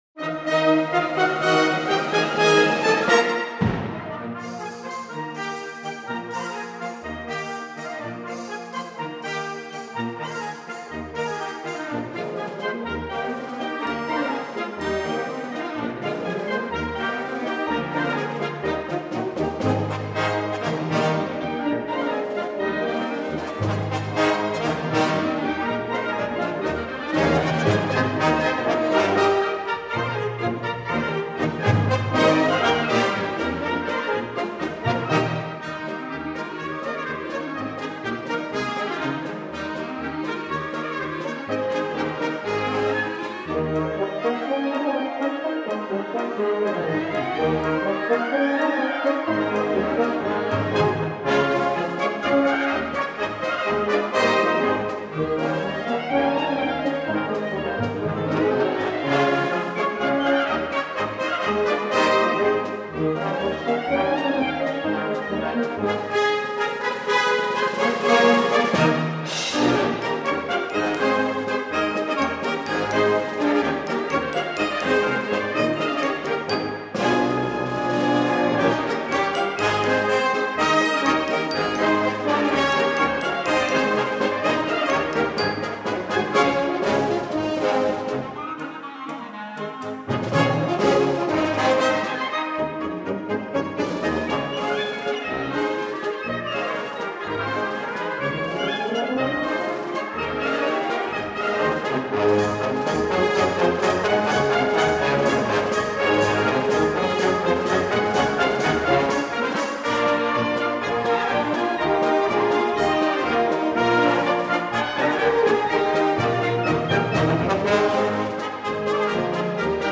Gattung: Polnischer Nationaltanz
Besetzung: Blasorchester